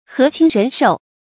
河清人壽 注音： ㄏㄜˊ ㄑㄧㄥ ㄖㄣˊ ㄕㄡˋ 讀音讀法： 意思解釋： 古時傳說黃河水千年一清，因以「河清人壽」極言人之長壽。